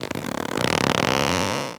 foley_leather_stretch_couch_chair_12.wav